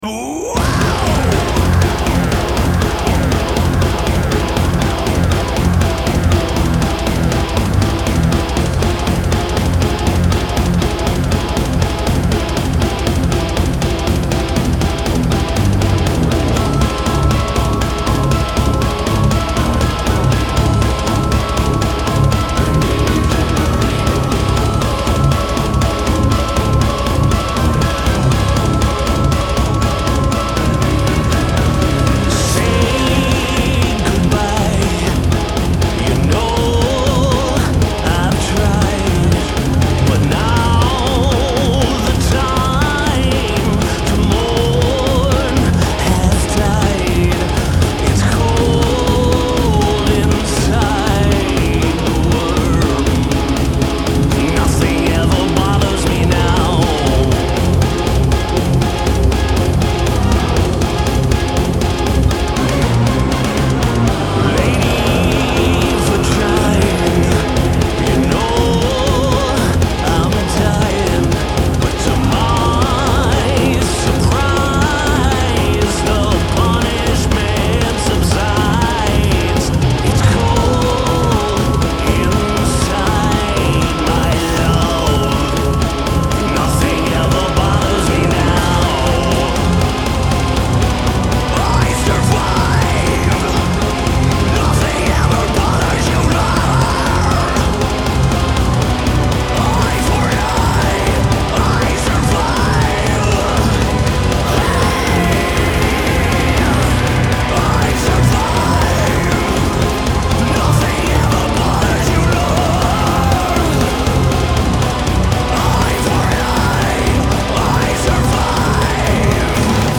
Genre : Progressive Rock, Progressive Metal